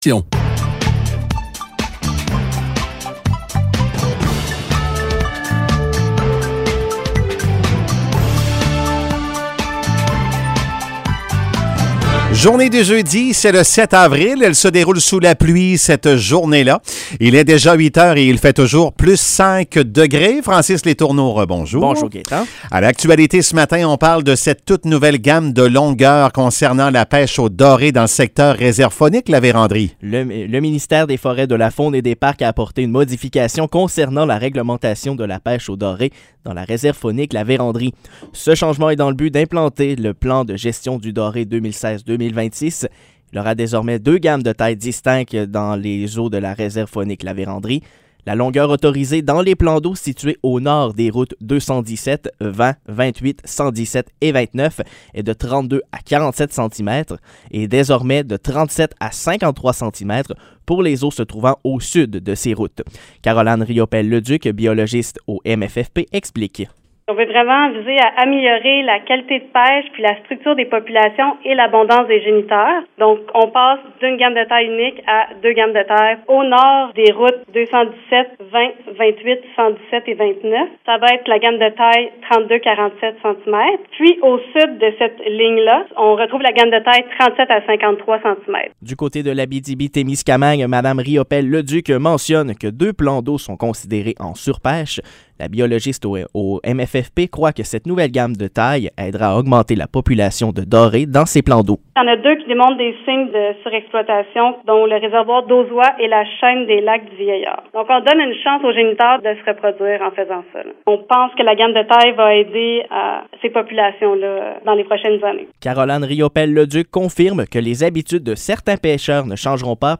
Nouvelles locales - 7 avril 2022 - 8 h